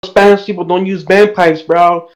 Bagpipe 3